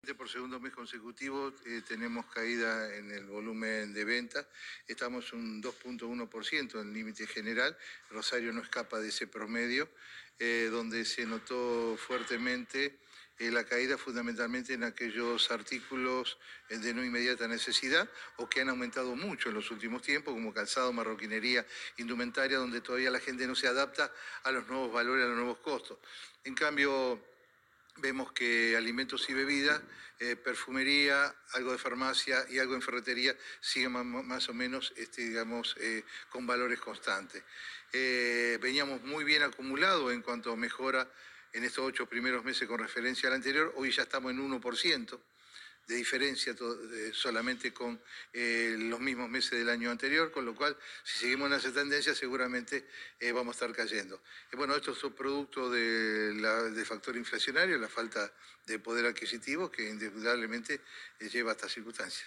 contó al móvil de Cadena 3 Rosario, en Radioinforme 3, que siguen bajando las ventas en general y en Rosario.